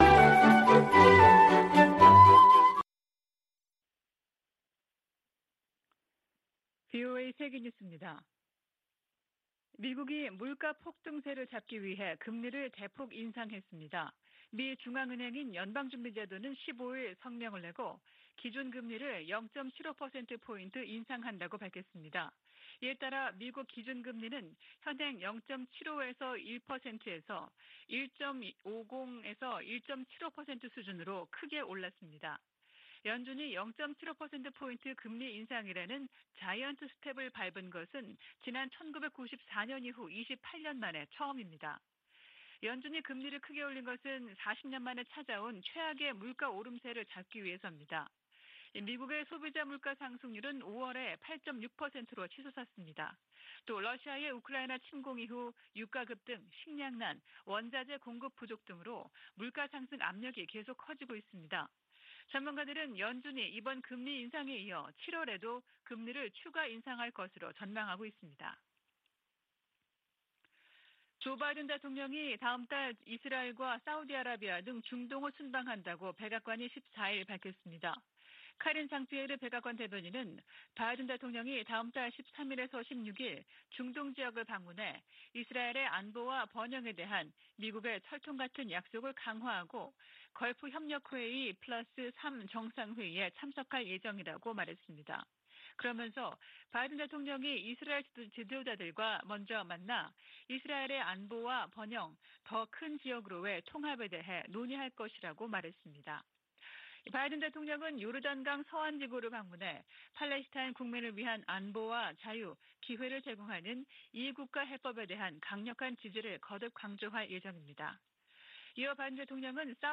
VOA 한국어 아침 뉴스 프로그램 '워싱턴 뉴스 광장' 2022년 6월 16일 방송입니다. 미국 정부 대북제재의 근거가 되는 '국가비상사태'가 다시 1년 연장됐습니다. 미 재무부 부장관은 북한의 거듭되는 무력시위에 응해 추가 제재 방안을 면밀히 검토하고 있다고 밝혔습니다. 미국은 한국·일본과 협의해 북한의 도발에 대한 장단기 군사대비태세를 조정할 것이라고 미 국방차관이 밝혔습니다.